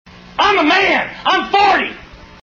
Play, download and share i'm 40 original sound button!!!!
man-im-40-a.mp3